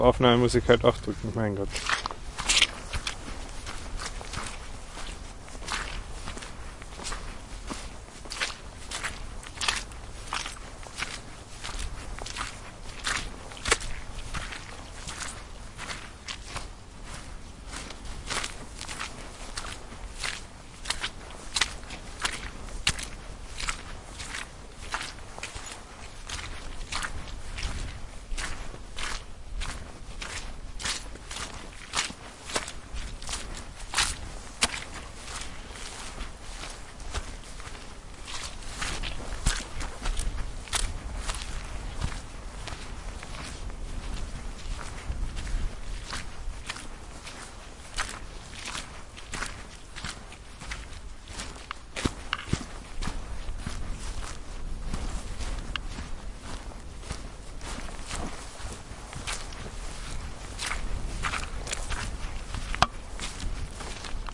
描述：步行穿过泥地，记录在法罗群岛DR05的法罗群岛远足之旅
标签： 远足 行走
声道立体声